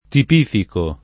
tipifico [ tip & fiko ], ‑chi